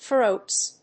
/θrots(米国英語), θrəʊts(英国英語)/